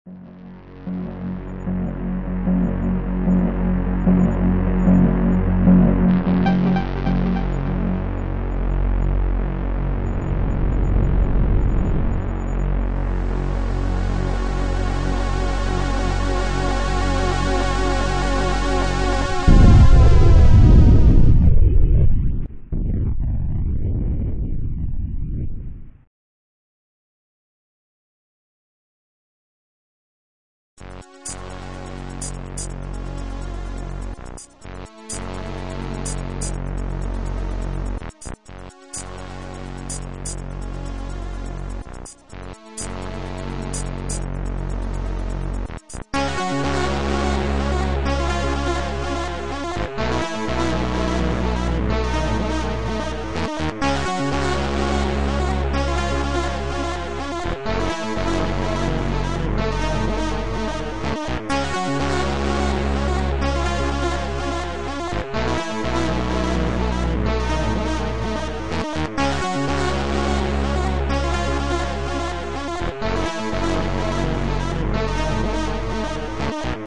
Digital title music
Features digitized title soundtrack at 7.7KHz